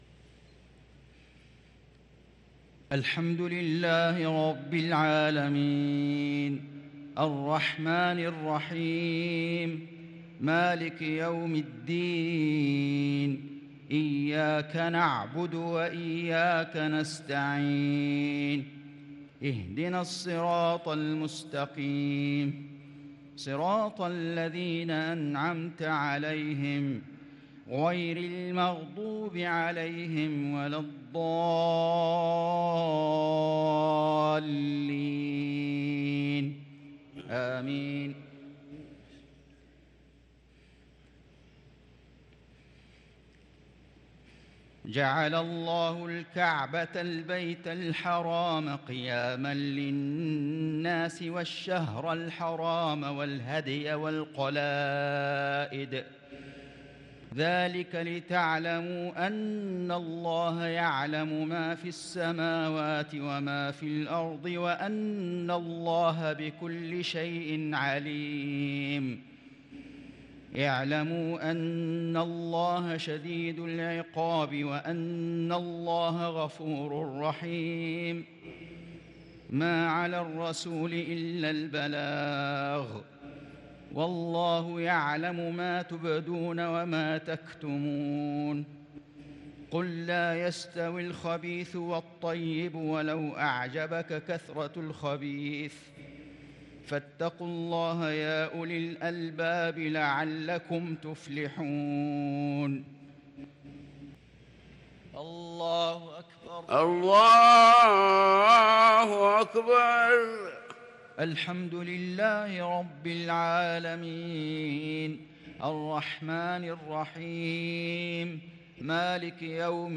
صلاة المغرب ٥ ذو القعدة ١٤٤٣هـ من سورتي المائدة و التوبة | Maghrib prayer from Surah al-Ma'idah & at-Taubah 4-6-2022 > 1443 🕋 > الفروض - تلاوات الحرمين